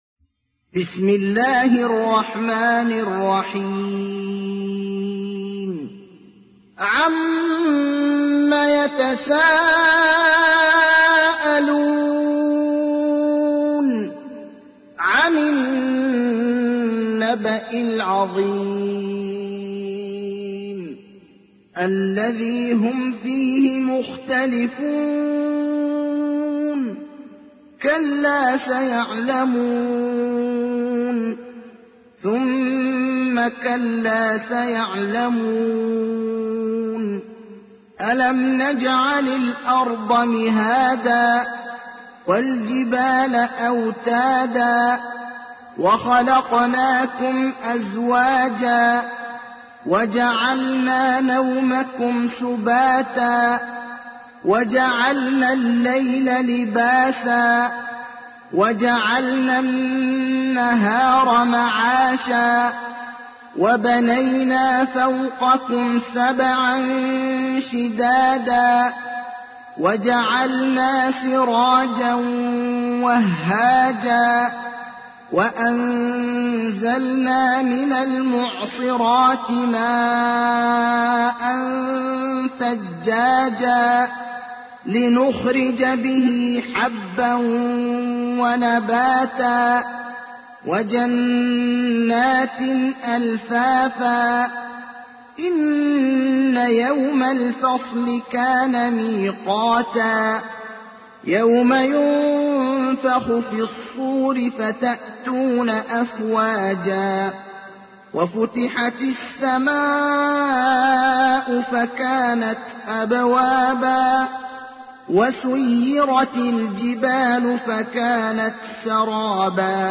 تحميل : 78. سورة النبأ / القارئ ابراهيم الأخضر / القرآن الكريم / موقع يا حسين